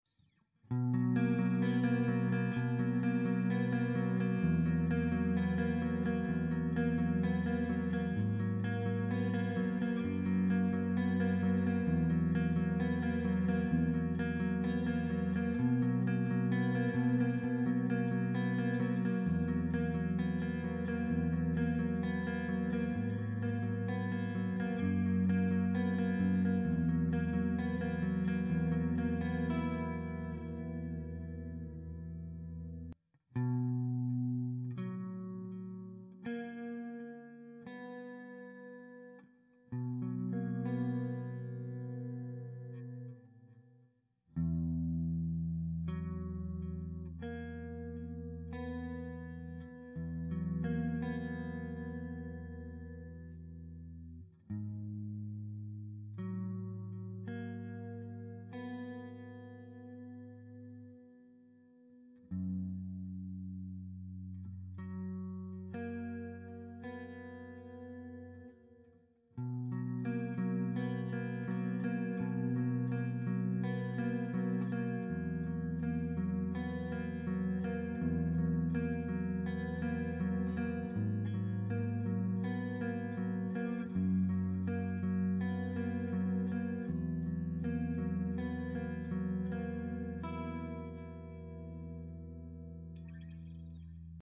Sweet Chords In B Major Sound Effects Free Download